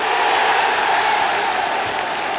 1 channel
00153_Sound_foule.mp3